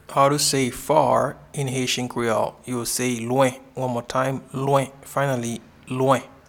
Pronunciation and Transcript:
Far-in-Haitian-Creole-Lwen.mp3